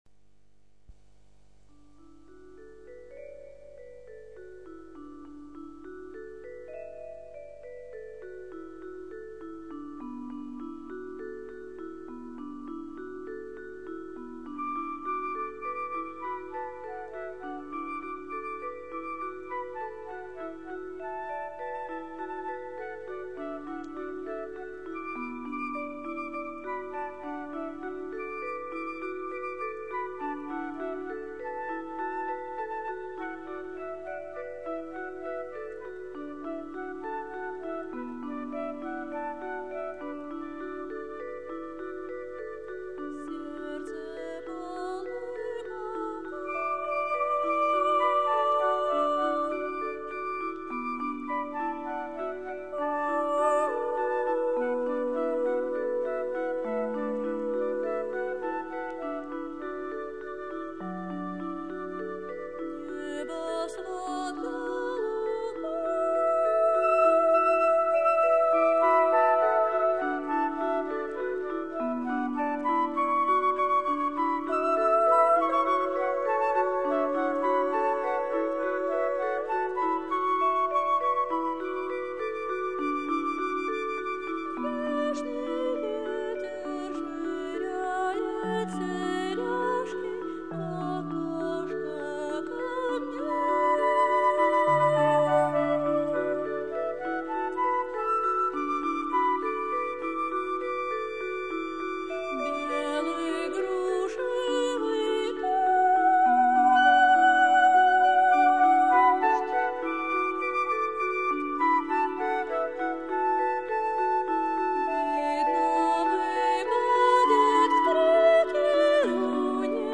Медитативное действо
Фондовая запись на Украинском радио.